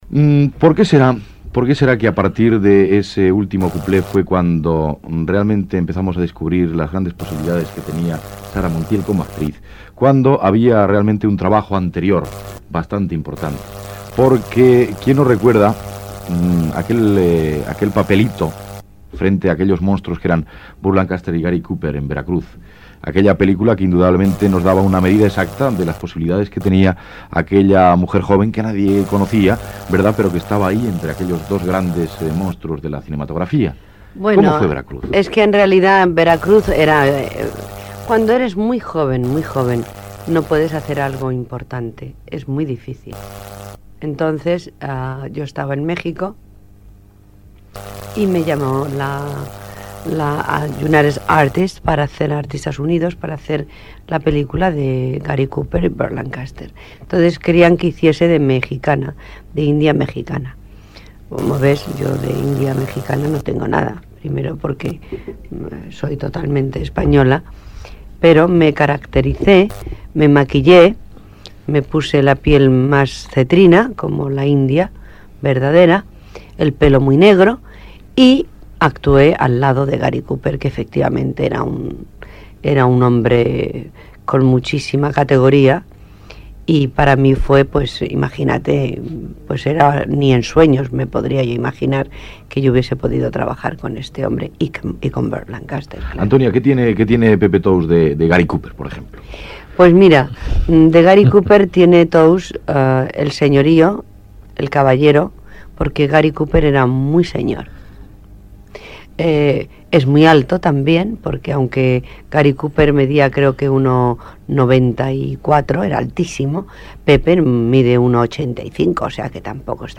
Entrevista a l'actriu Sara Montiel que parla de la pel·lícula "Veracruz"
Entreteniment